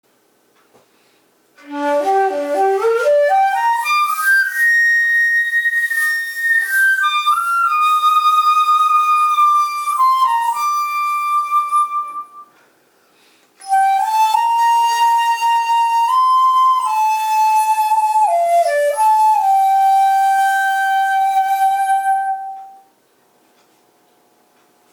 考えあぐねている内に「尺八はカザシ(翳し）」の技法があることに気付き、それならB♭の運指から第１孔をかざしてAを作り次の大甲レにつなげればいいとわかりました。